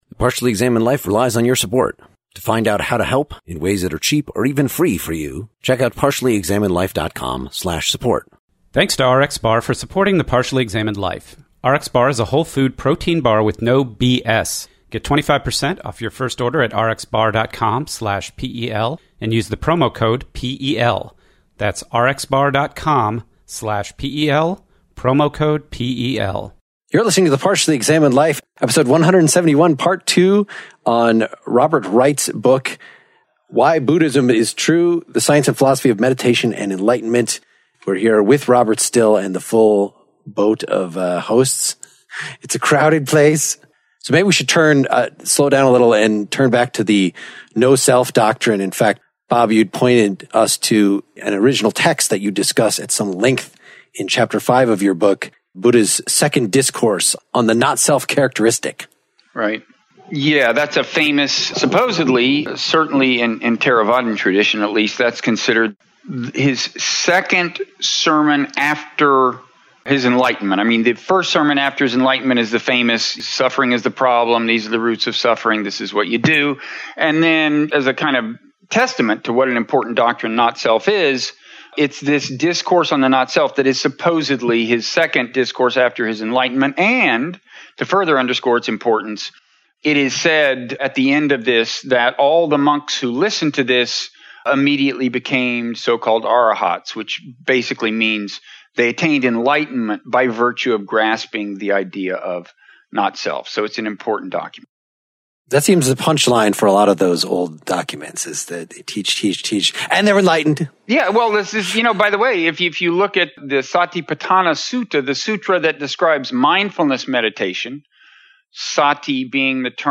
Buddhism vs. Evolution with Guest Robert Wright (Part Two)